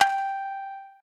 shamisen_g.ogg